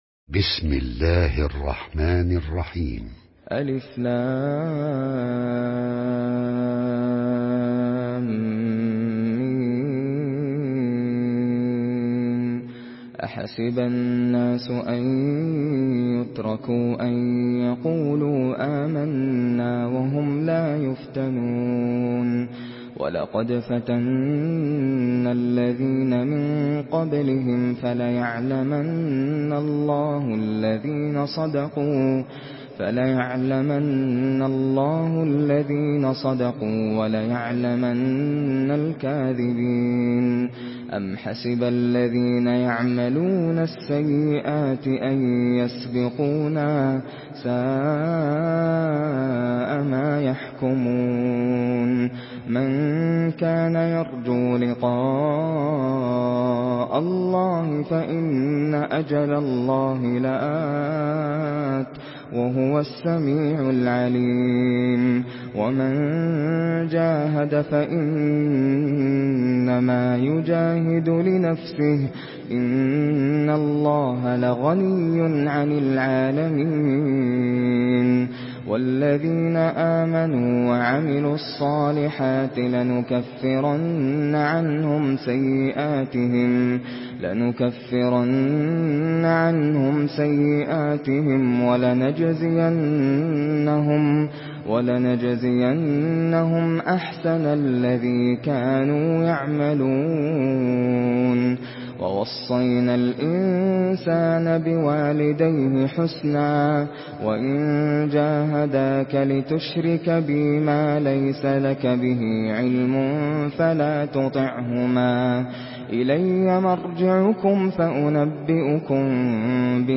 Surah العنكبوت MP3 by ناصر القطامي in حفص عن عاصم narration.
مرتل حفص عن عاصم